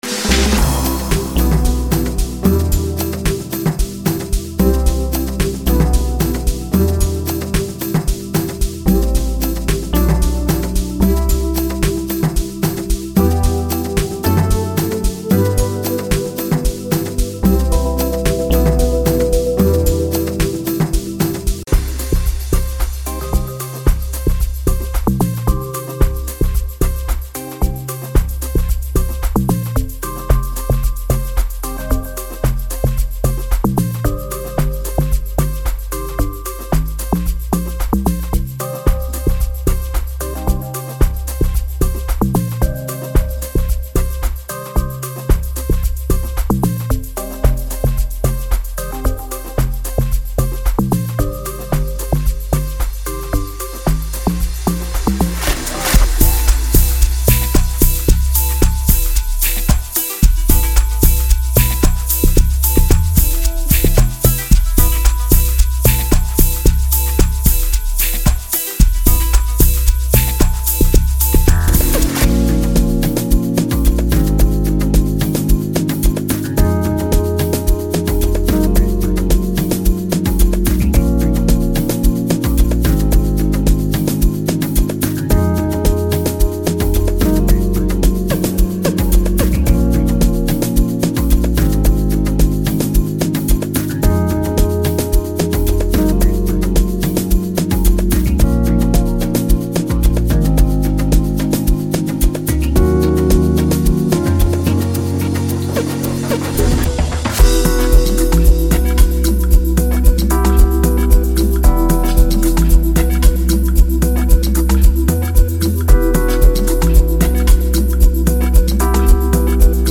Amapiano Sample Pack